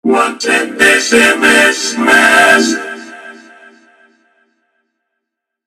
Nada dering Wa Bahasa Jawa “Wonten SMS Mas”
Kategori: Nada dering
Keterangan: Ringtone WhatsApp Bahasa Jawa "Wonten SMS Mas"...